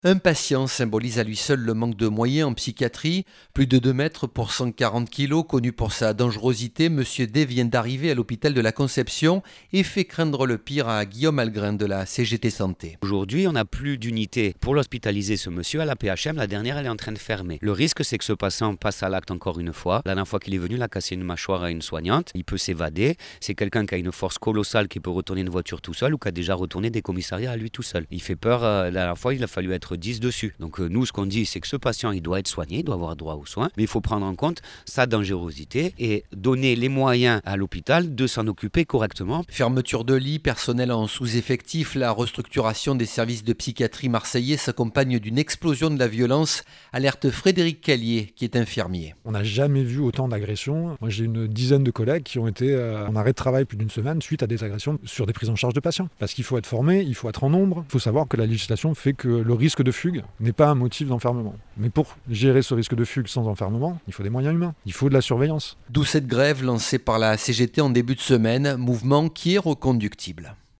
Reportage à Marseille